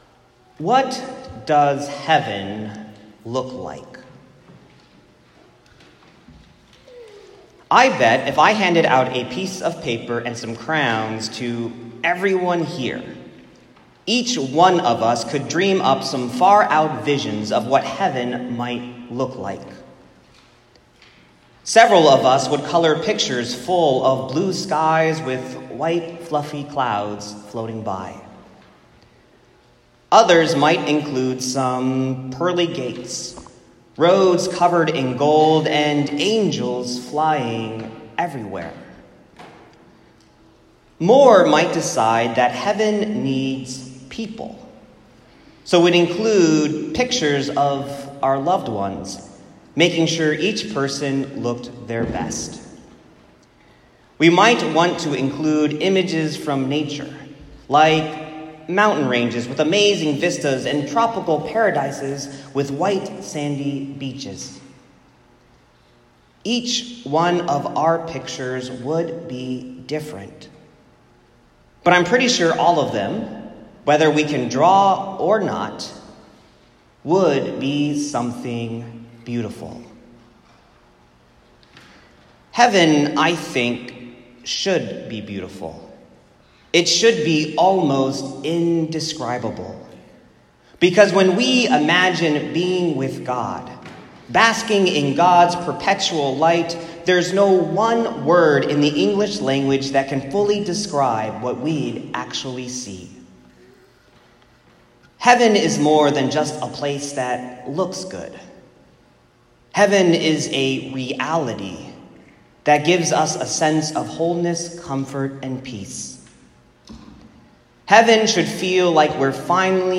Isaiah 6:1-8 My sermon from Trinity Sunday (May 27, 2018) on Isaiah 6:1-8.